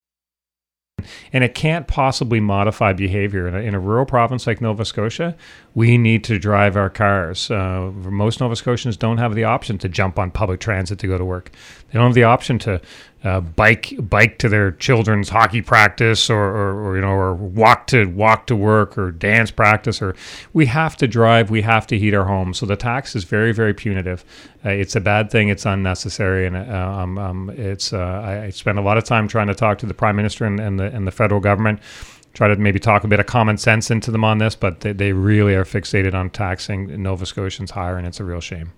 Tim Houston, premier ministre de la Nouvelle-Écosse et Allan MacMaster, vice-premier ministre et député pour le comté d'Inverness étaient de passage dans les studios de Radio CKJM à Chéticamp.